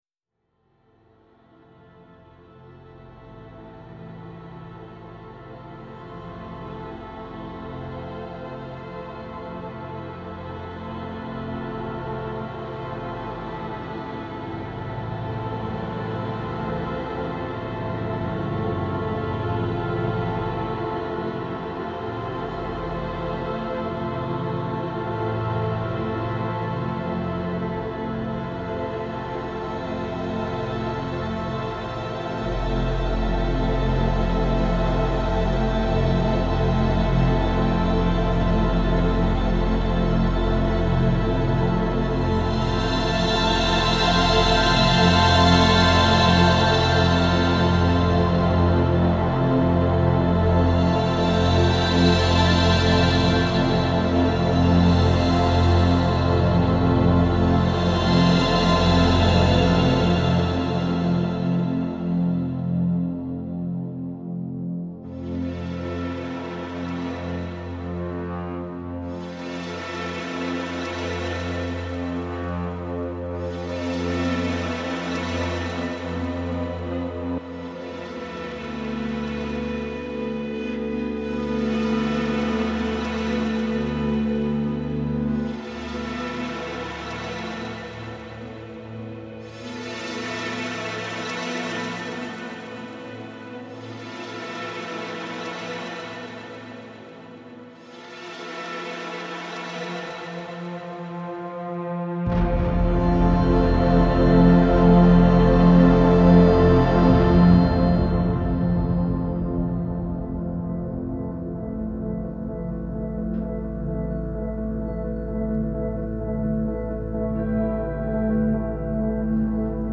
Genre : FilmsGames, Film Scores